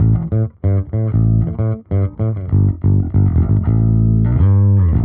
Index of /musicradar/dusty-funk-samples/Bass/95bpm
DF_JaBass_95-A.wav